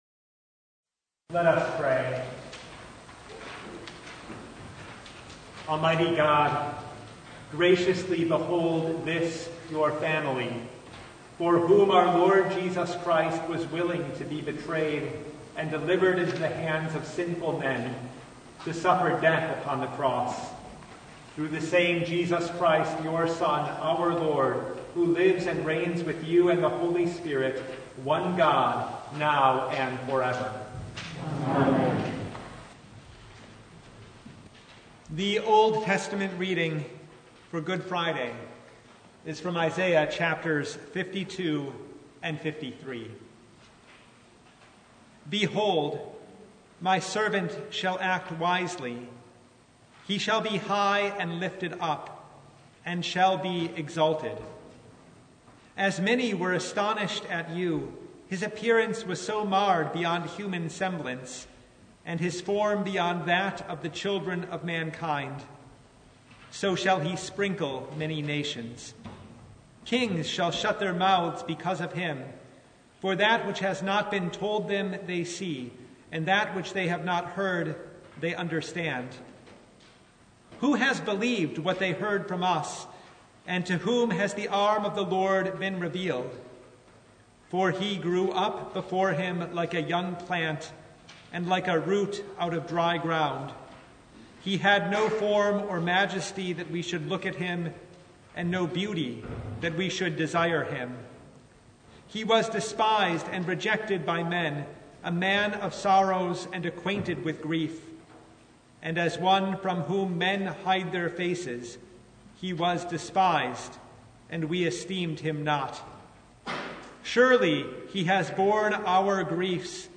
Good Friday Noon Service (audio recording)